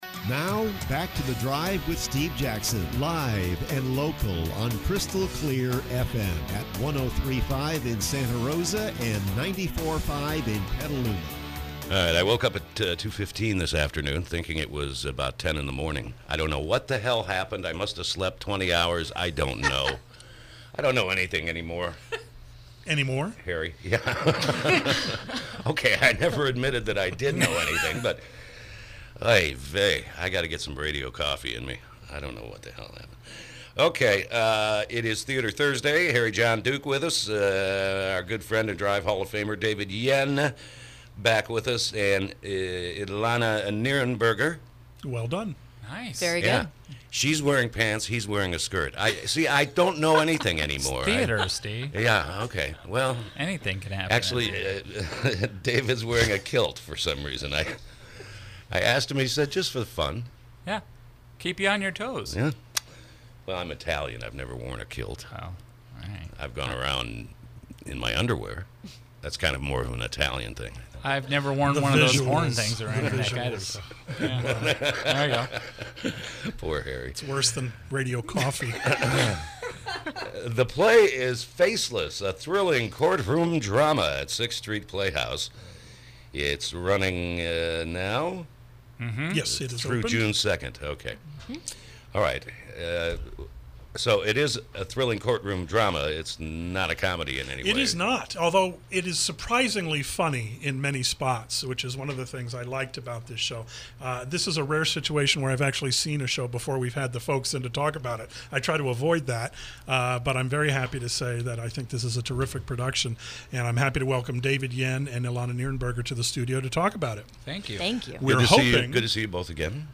KSRO Interview: “Faceless”